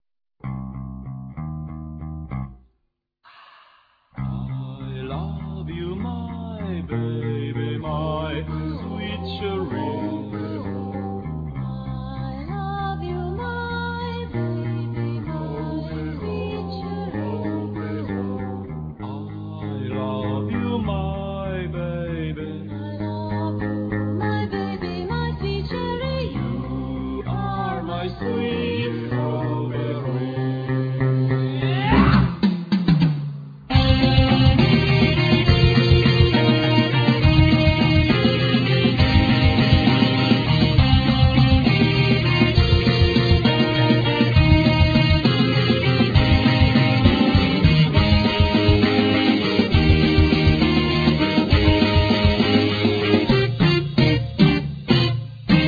Guitar,Keyboards,Vocal
Tennor saxophne,Vocal
Bass,Vocal
Brushes,Stetce